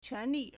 权力 (權力) quánlì
quan2li4.mp3